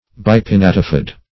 Bipinnatifid \Bi`pin*nat"i*fid\, a. [Pref. bi- + pinnatifid.]